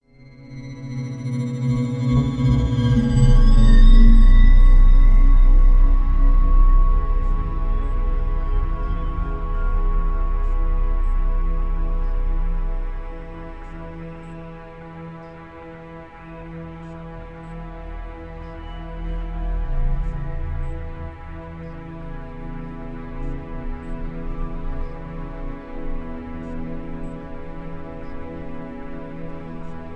Background music suitable for TV/Film use.